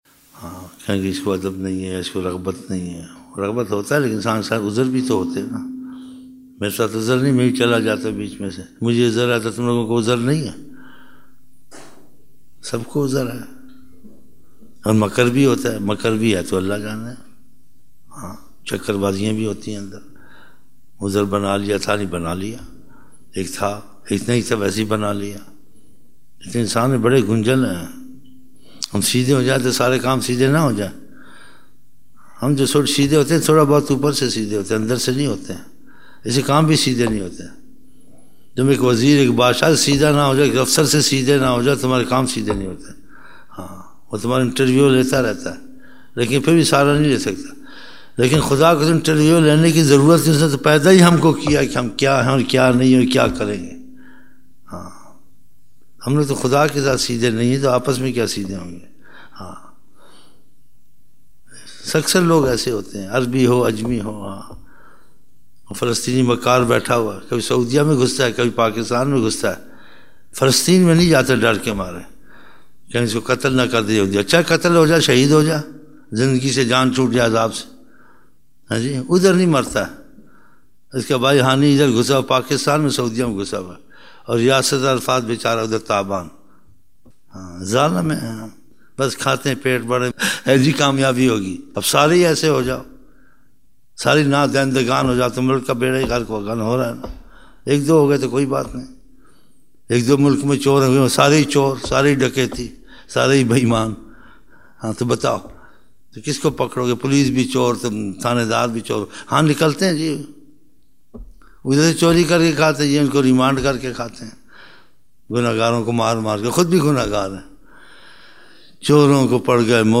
17 November 1999 - Fajar mehfil (9 Shaban 1420)